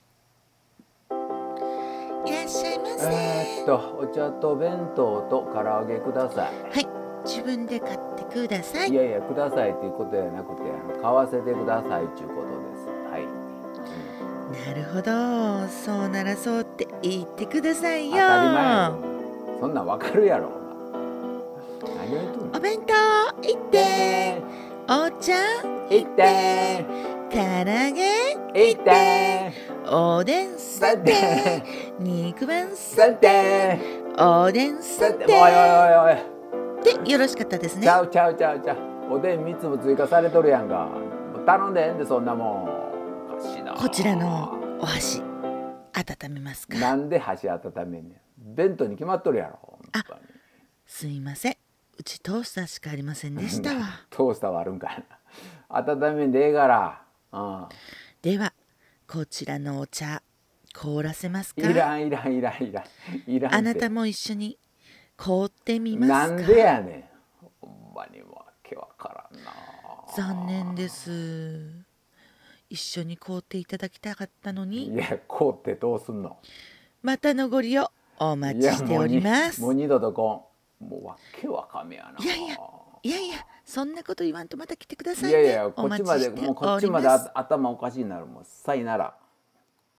【二人声劇台本】コンビニ【ギャグ声劇】コラボ募集